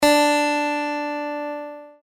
Use the audio tones below to tune your guitar to an Open G Tuning (commonly used for playing slide).
High D String